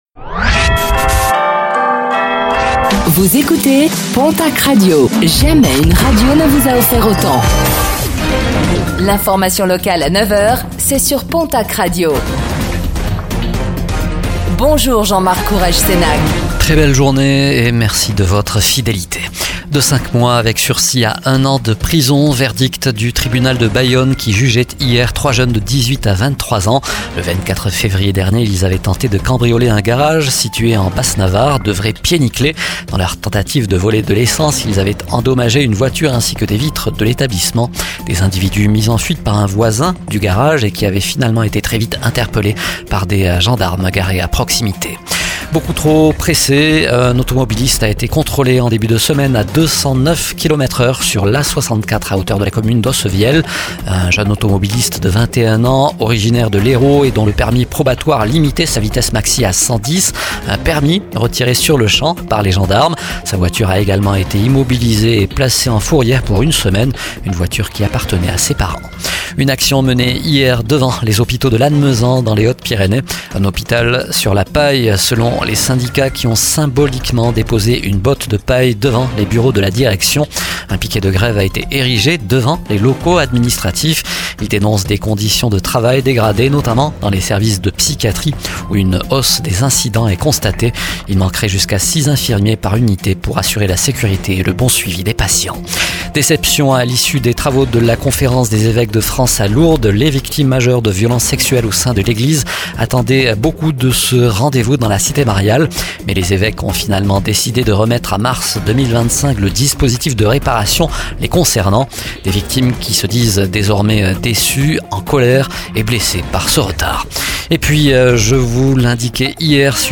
Réécoutez le flash d'information locale de ce mercredi 13 novembre 2024